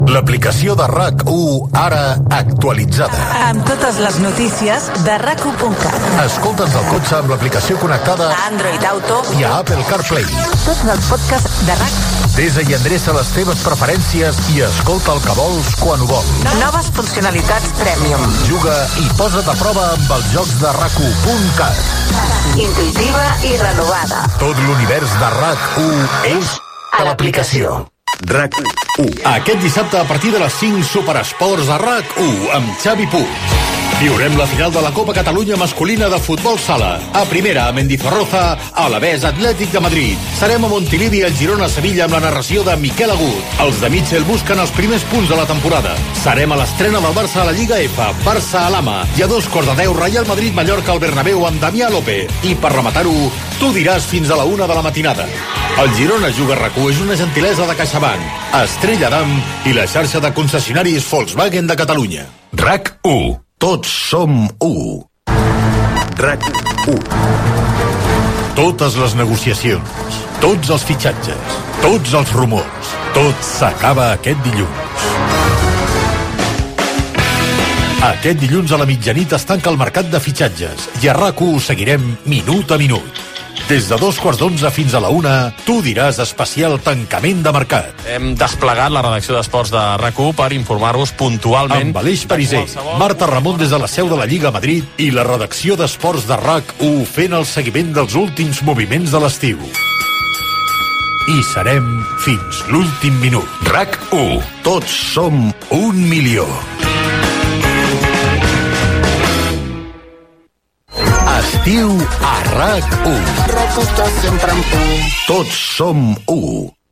Anunci de l'actualització de l'apliació de RAC 1, promoció del programa "Superesports", indicatiu de la ràdio, promoció de l'especial tancament de fitxatges del programa "Tu diràs", indicatiu estiuenc de la ràdio
FM